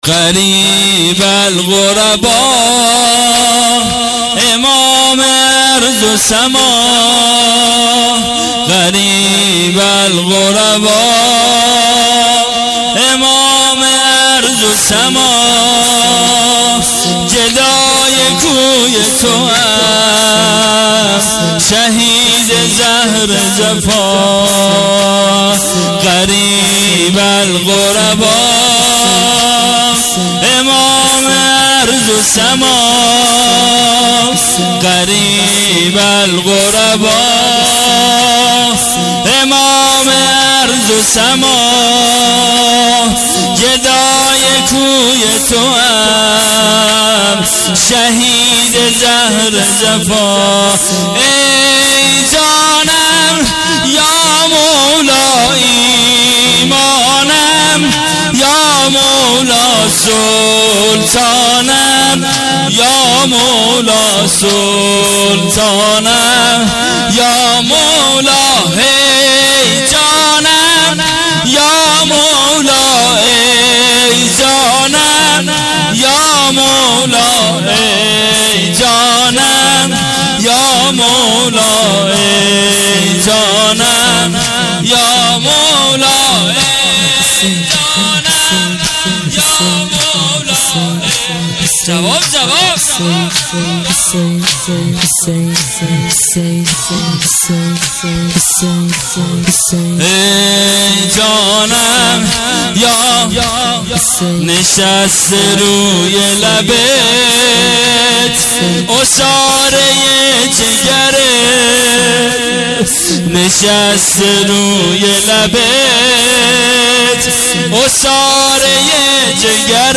عزای شهادت امام رضا(ع)۹۸